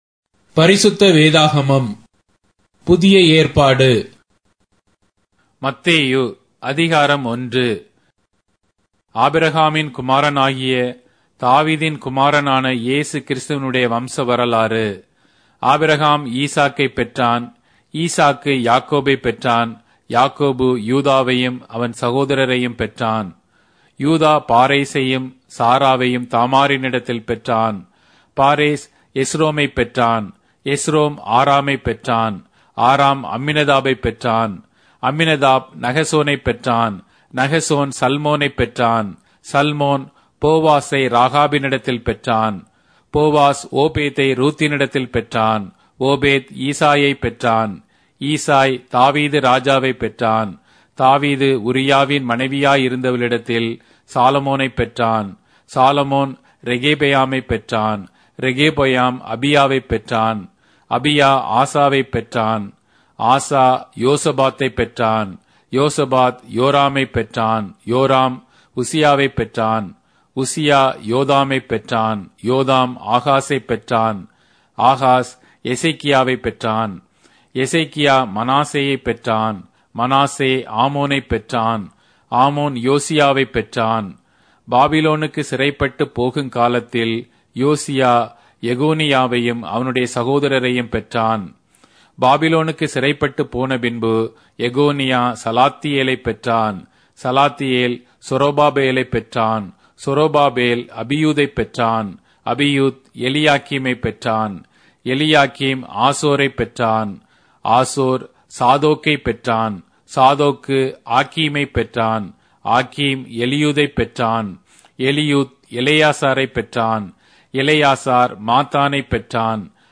Tamil Audio Bible - Matthew 28 in Tov bible version